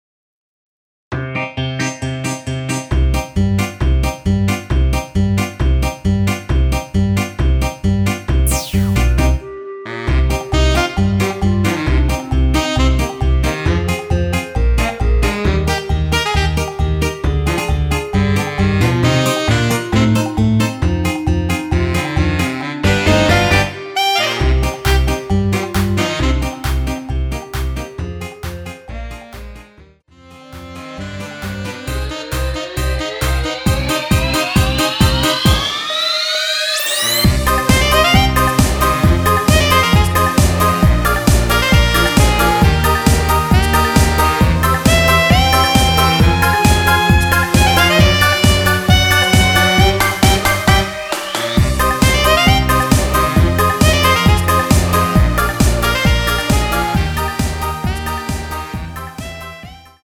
원키에서(-6)내린 멜로디 포함된 MR입니다.
Cm
앞부분30초, 뒷부분30초씩 편집해서 올려 드리고 있습니다.
중간에 음이 끈어지고 다시 나오는 이유는